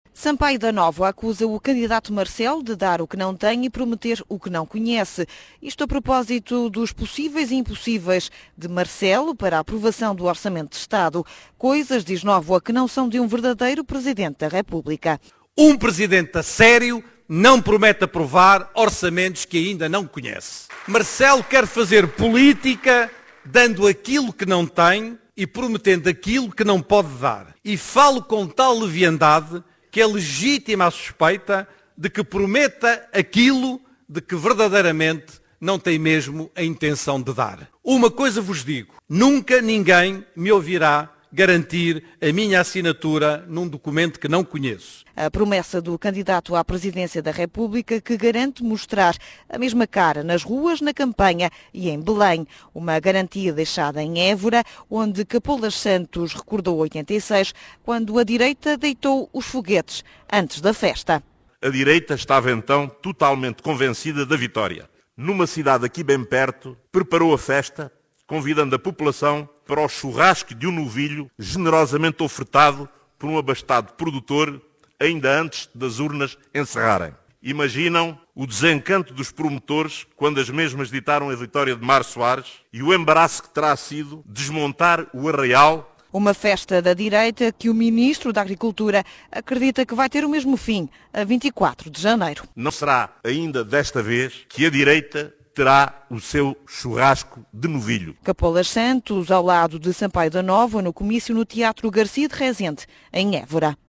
Num comício nocturno em Évora, Sampaio da Nóvoa acusou o seu adversário na corrida a Belém de dar o que não tem e prometer o que desconhece.